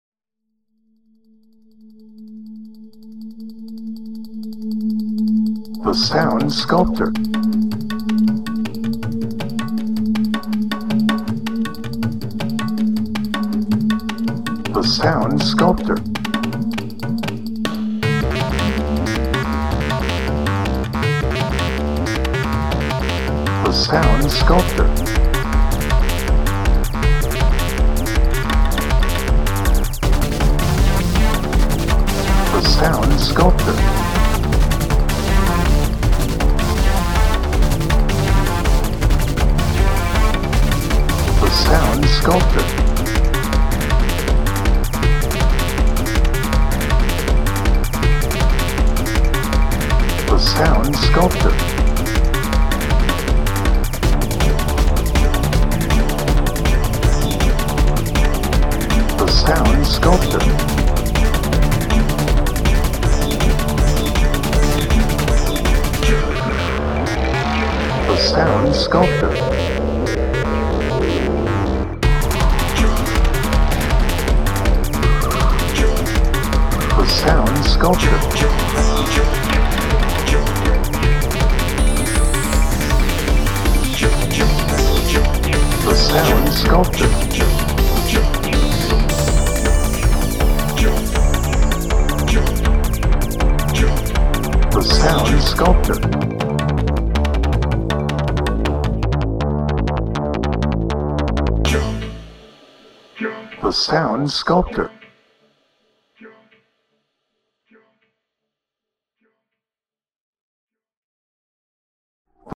Chase
Edgy
Electronic
Speed
Tense